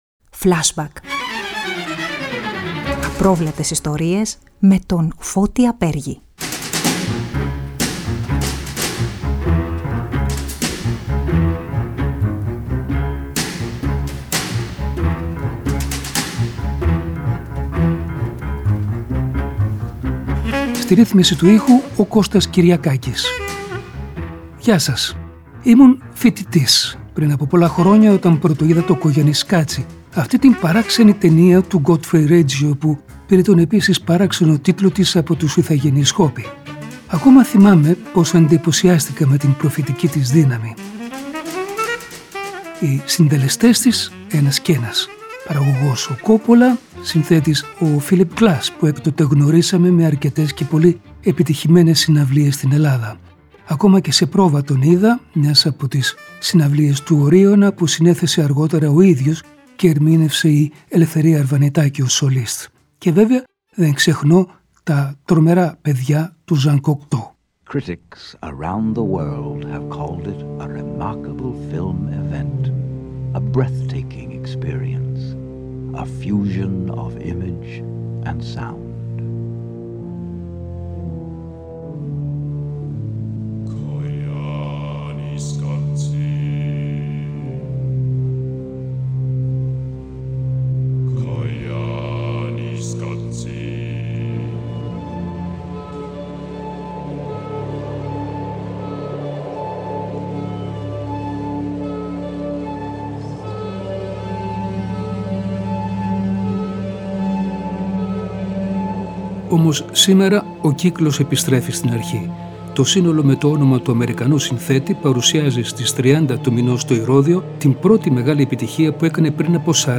αποσπάσματα από δυο συνεντεύξεις που έχει πάρει παλαιότερα από τον καταξιωμένο δημιουργό